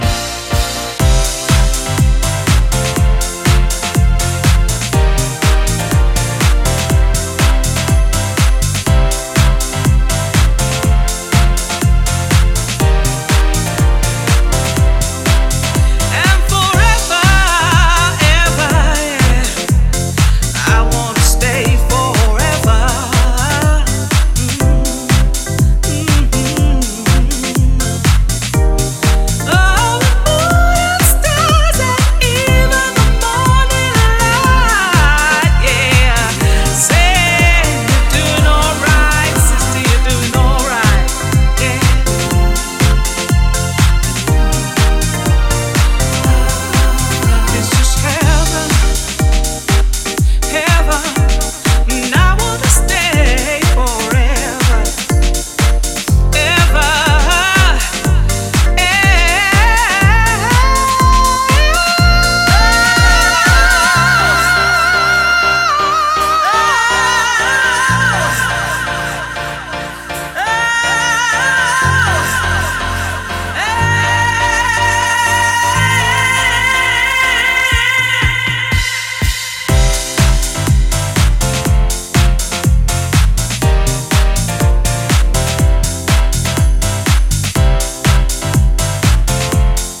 力強く高揚していくフィーリングが増強されたゴスペル・ハウス！
ジャンル(スタイル) DEEP HOUSE / SOULFUL HOUSE / HOUSE